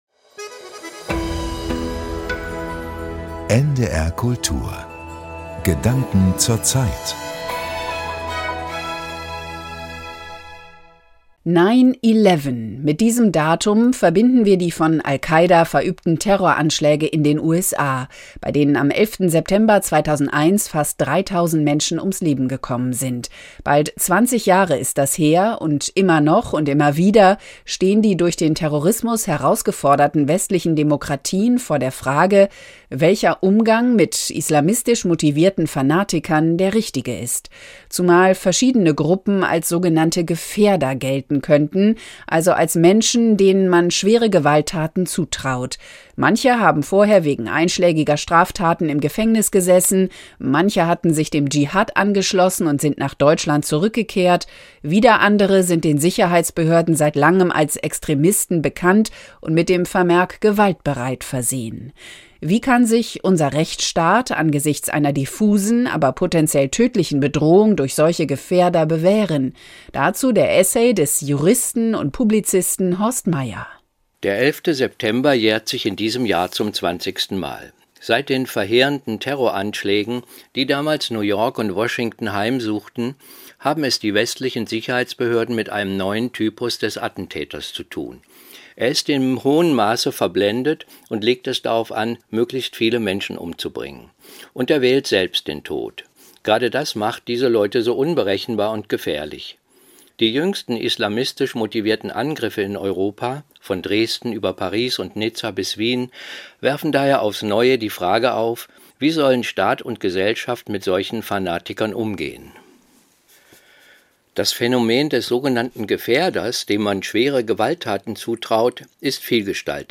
Essay Gedanken zur Zeit, NDR Kultur, 13.